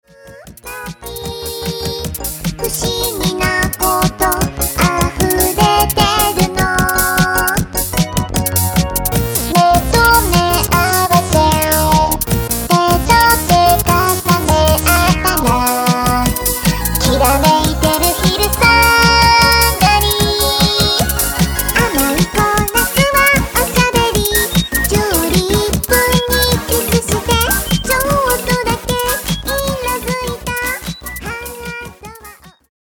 ギター